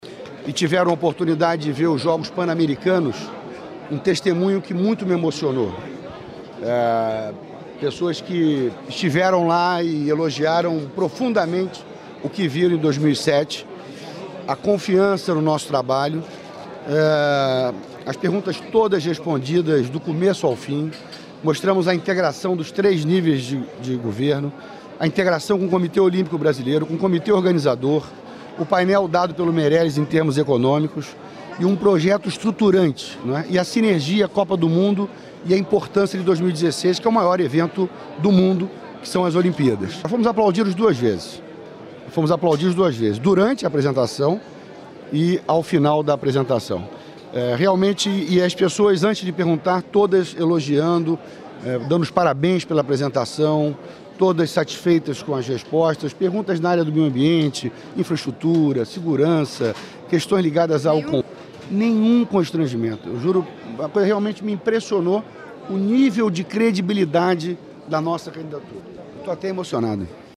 Governandor do Rio de Janeiro fala, minutos depois, da candidatura aos JO de 2016 diante da Comissão Executiva do COI, em Lausanne